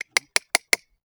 TOOL_Chisel_Sequence_01_mono.wav